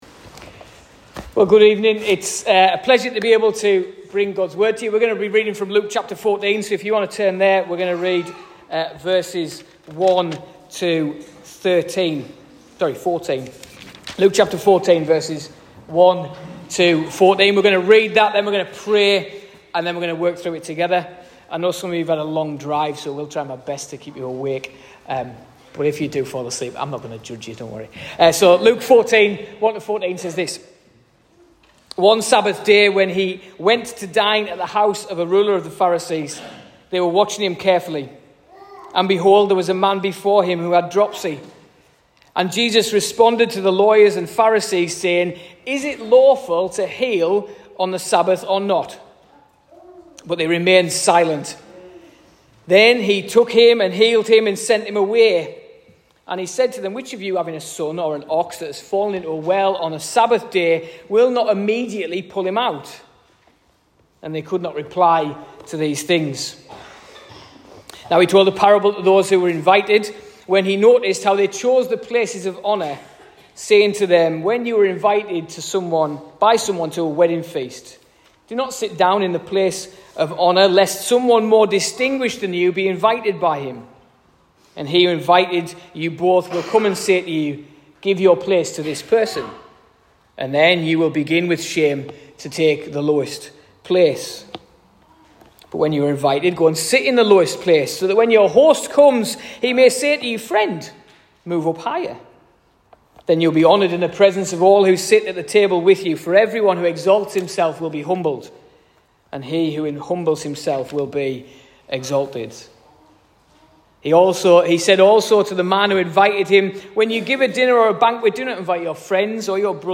#85: Where Will You Sit? A sermon
at the Medhurst Weekender 2024